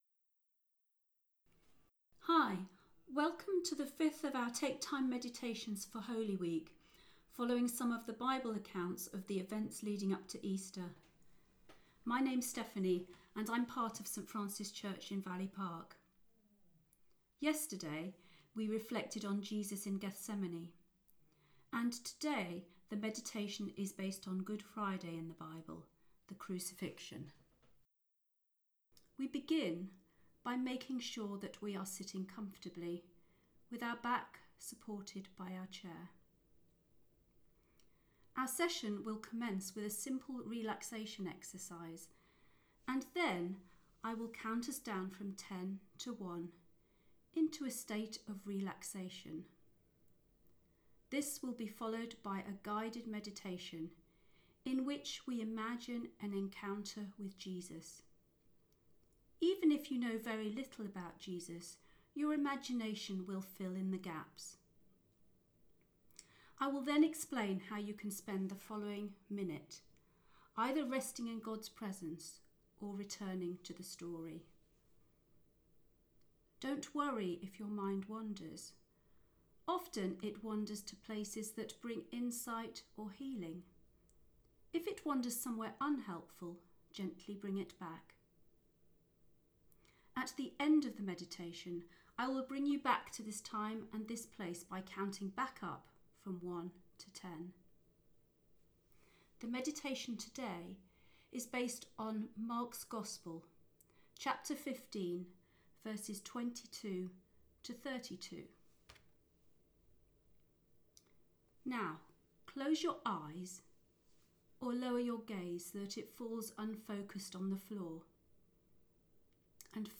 Holy Week Meditation - Good Friday